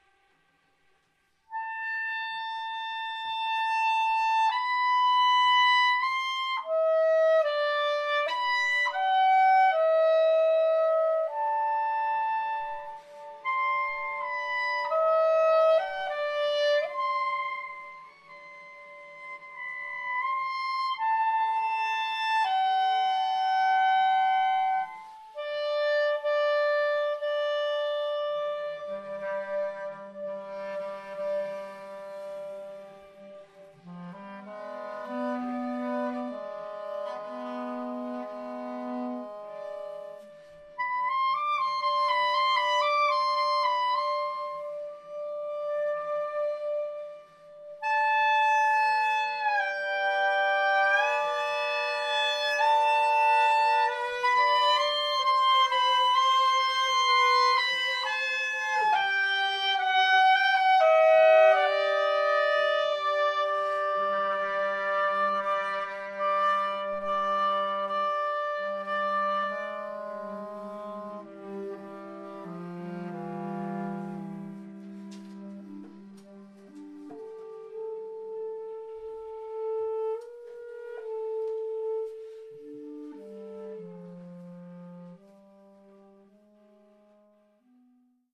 für Flöte, Klarinette und Violoncello
Flöte, Klarinette in B, Violoncello